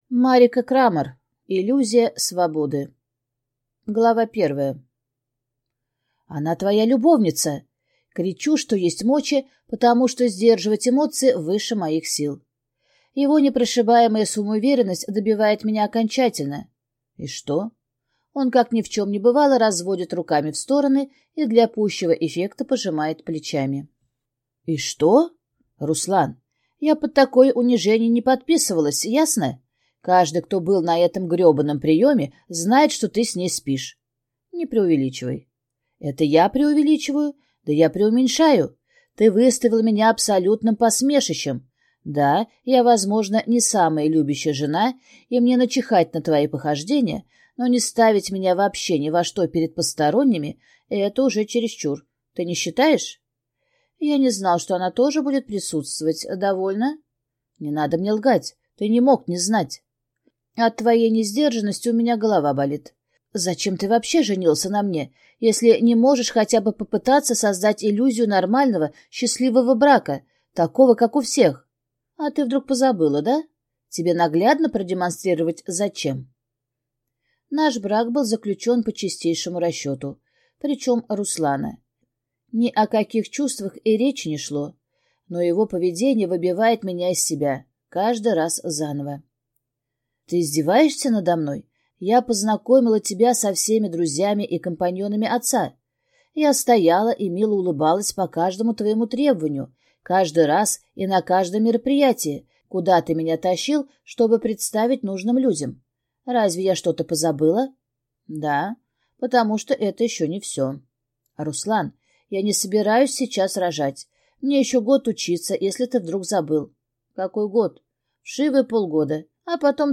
Аудиокнига Иллюзия свободы | Библиотека аудиокниг